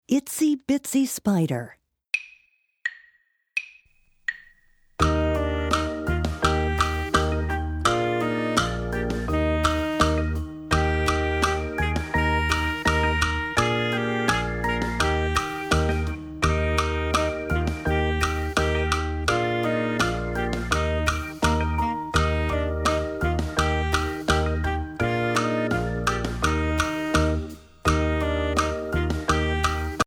Voicing: Drums